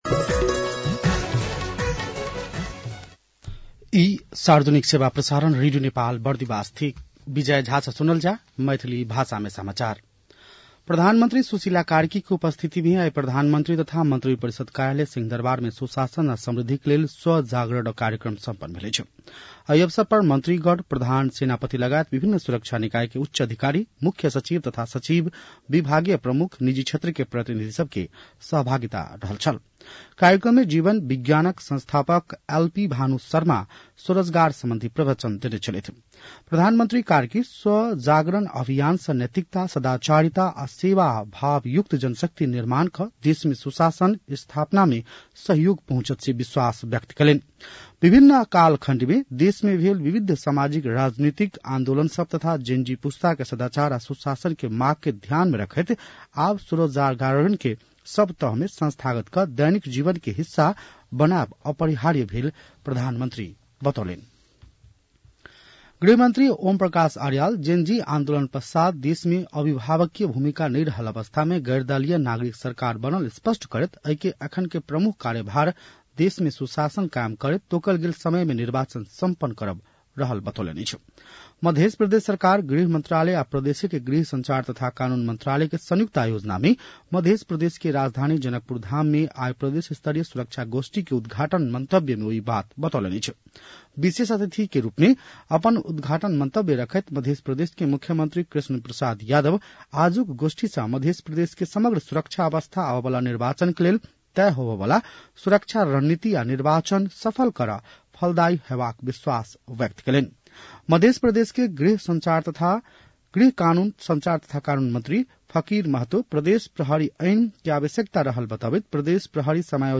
मैथिली भाषामा समाचार : ३० पुष , २०८२
Maithali-news-9-30.mp3